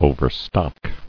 [o·ver·stock]